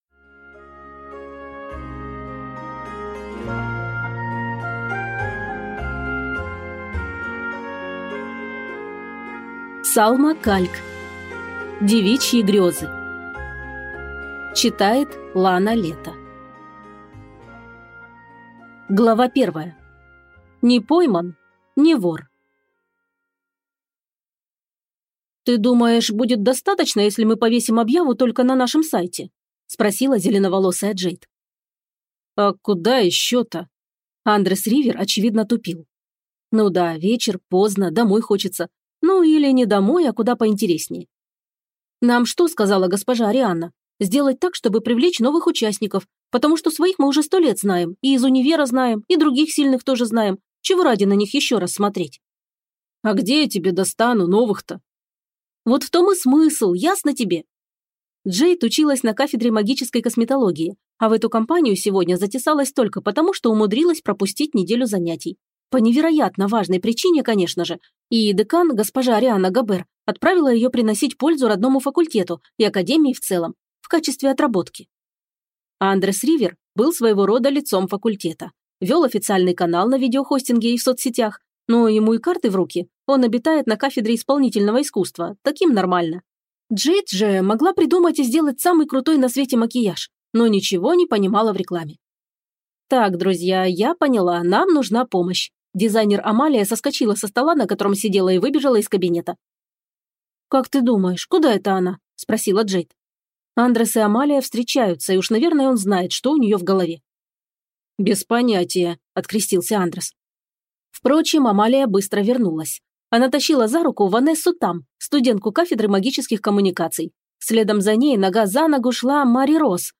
Аудиокнига Девичьи грёзы | Библиотека аудиокниг